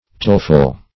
Taleful \Tale"ful\ (t[=a]l"f[.u]l)